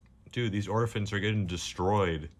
Play, download and share Techno-Jump original sound button!!!!
techno-jump.mp3